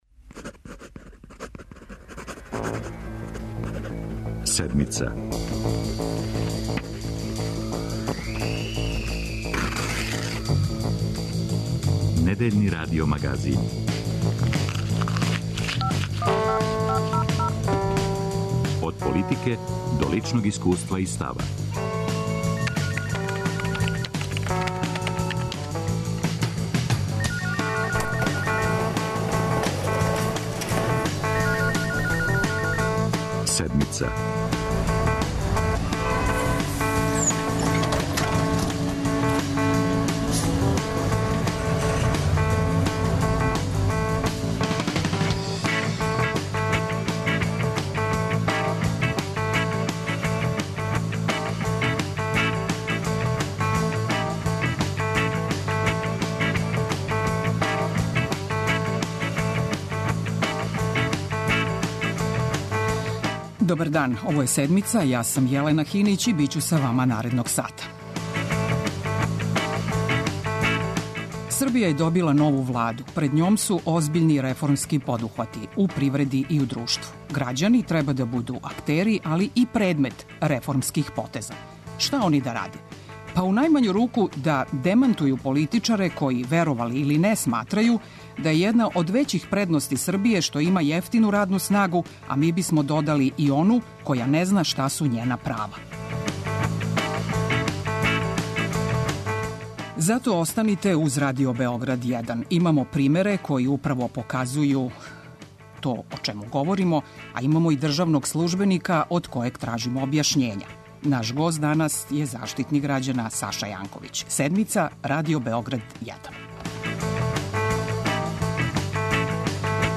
Шта су обавезе нове Владе Србије у погледу заштите људских права за Седмицу говори Заштитник Саша Јанковић.